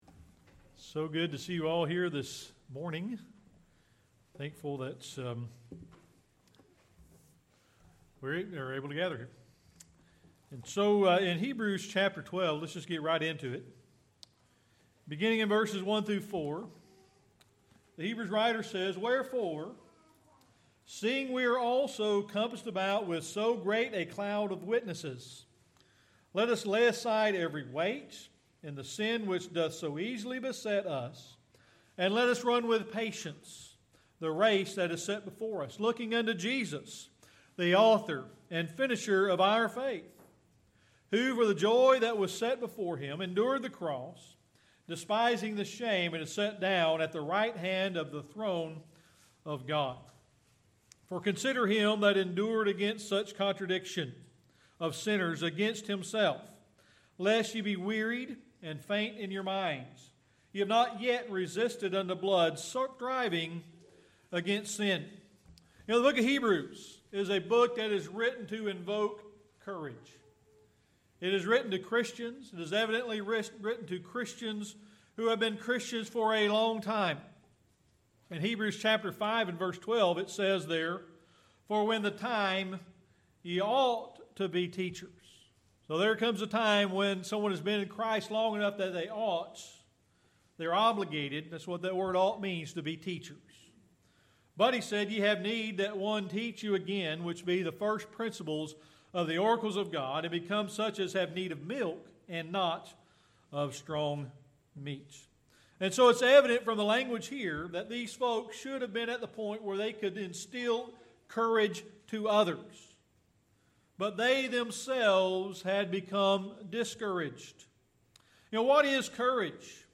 July 26, 2020 Series: Sermon Archives Passage: Hebrews 12:1-4 Service Type: Sunday Morning Worship In Hebrews 12:1-4 The book of Hebrews is written to Christians to evoke courage.